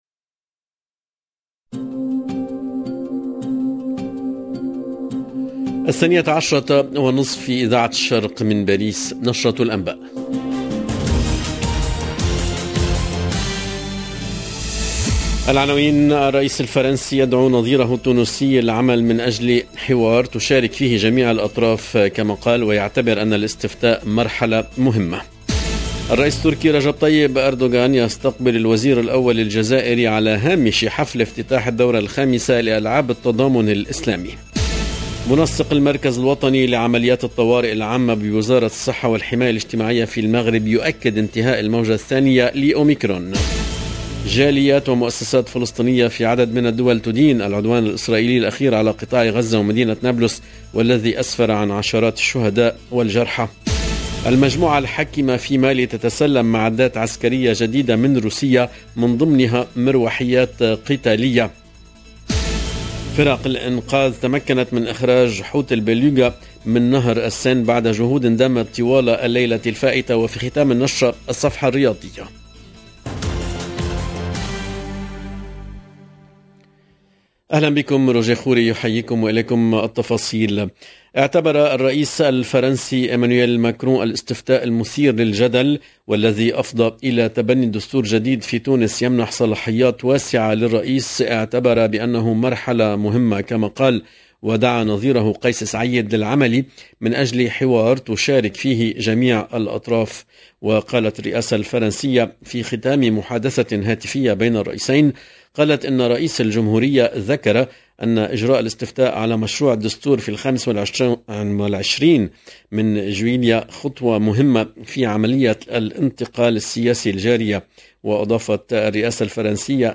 LE JOURNAL EN LANGUE ARABE DE MIDI 30 DU 10/08/22